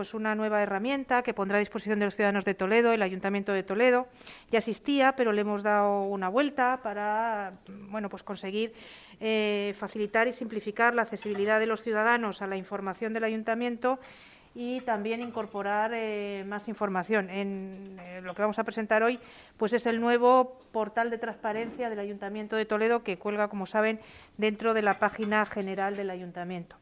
AUDIOS. Mar Álvarez, concejala de Hacienda